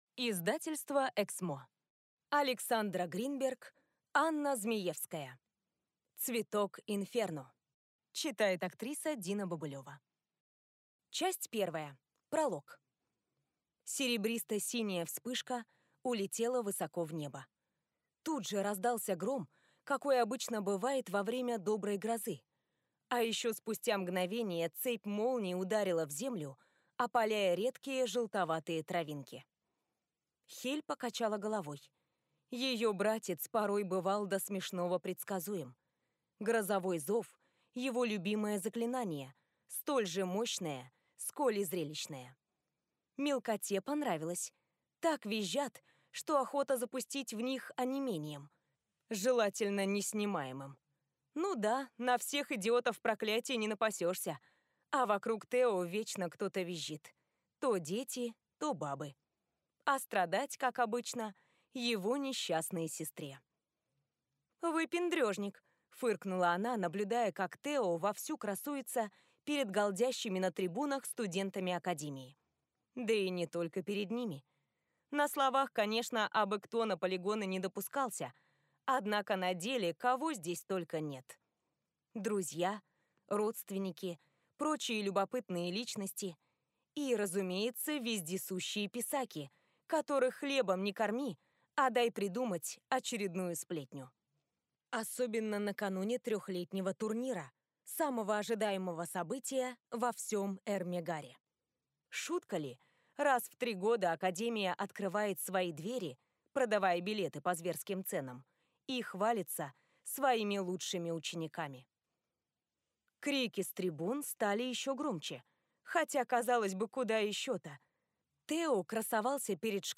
Аудиокнига Цветок Инферно | Библиотека аудиокниг